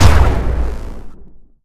poly_explosion_nuke2.wav